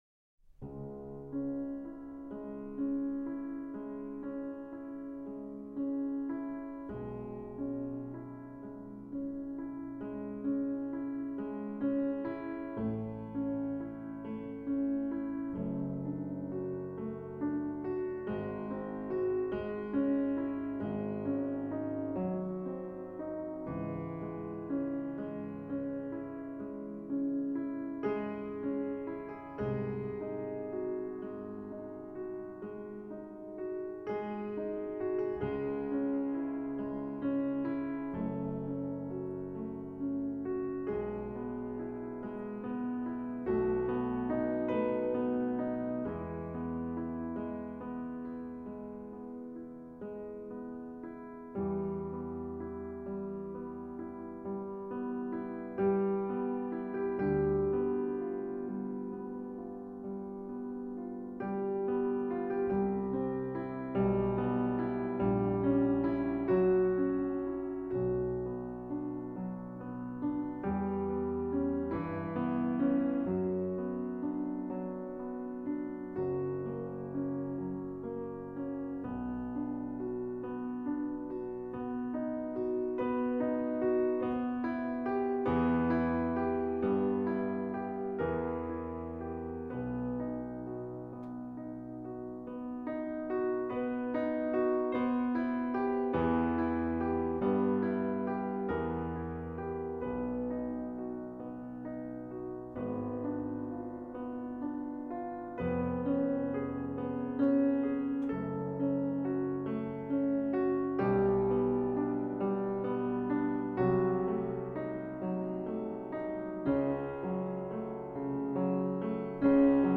Sonata -C Major K. 545,Wolfgang Amadeus Mozart-Andante